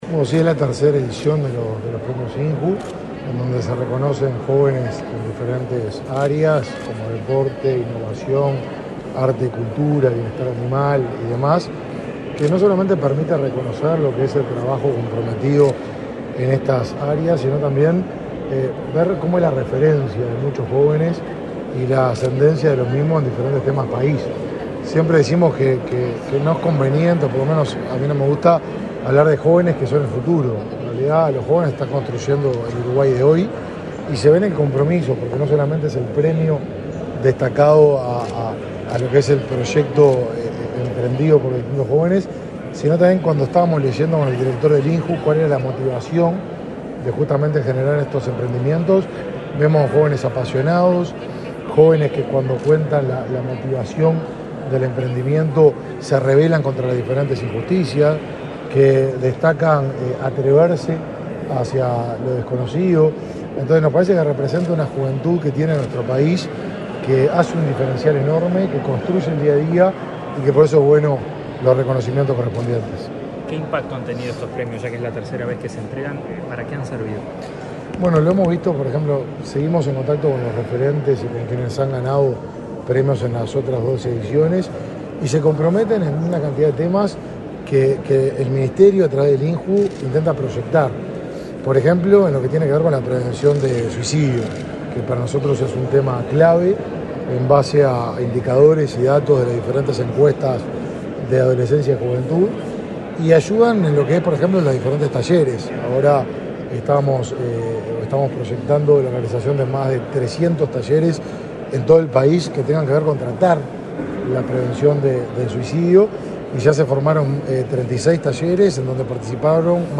Declaraciones a la prensa del ministro Martín Lema
En la oportunidad, realizó declaraciones a la prensa.